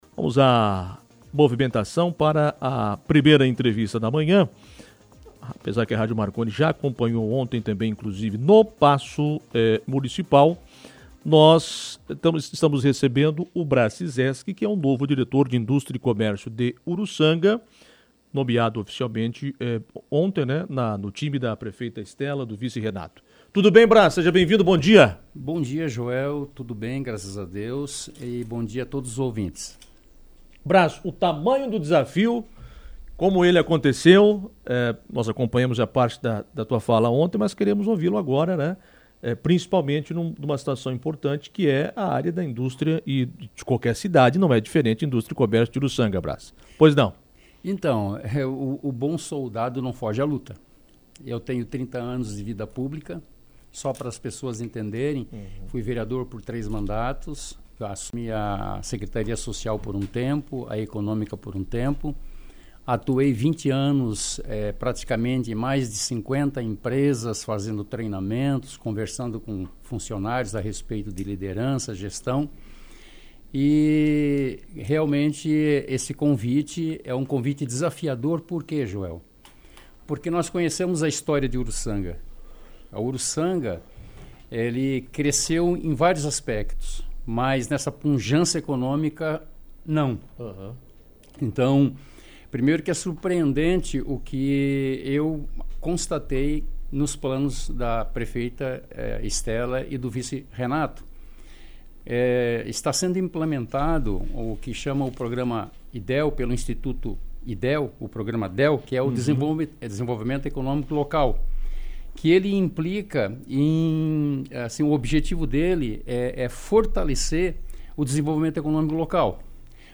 Braz destacou mais sobre os próximos projetos da Diretoria de Indústria e Comércio em entrevista